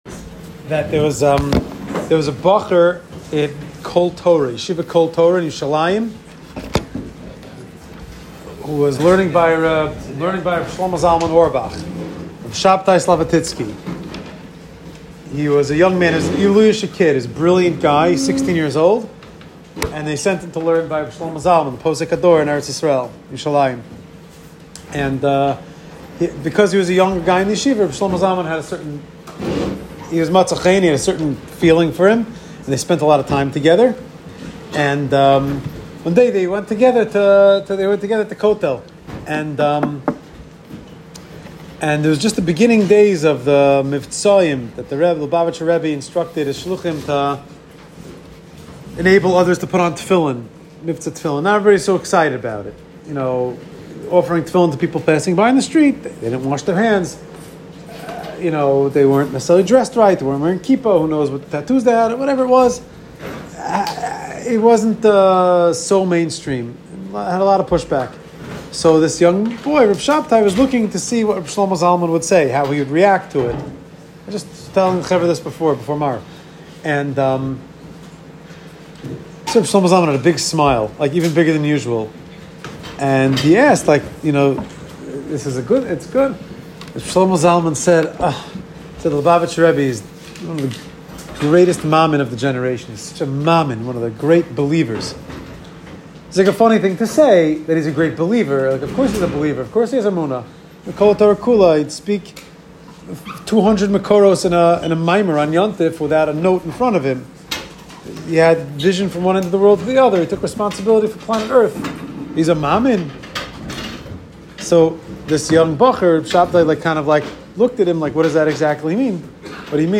Shiur @ Camp Hasc